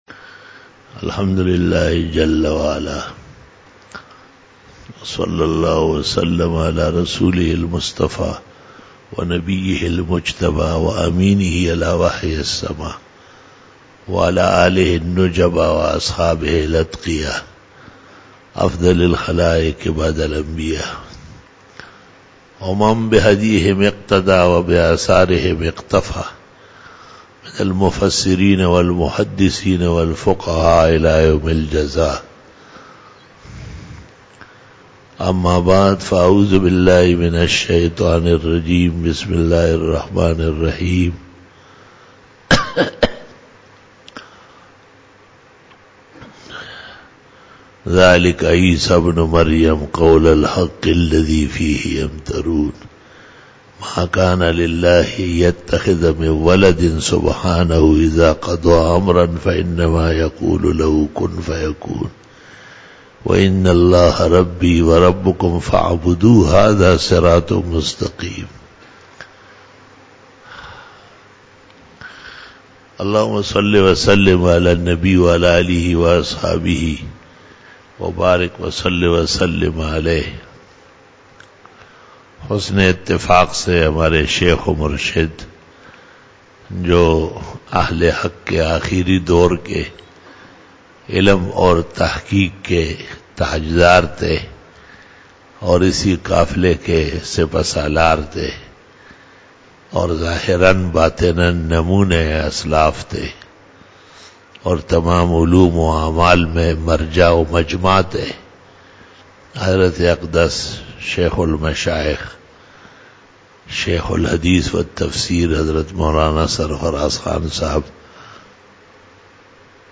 07 BAYAN E JUMA TUL MUBARAK 14 FEBRUARY 2020 (19 Jamadi Us Sani 1441H)
Khitab-e-Jummah 2020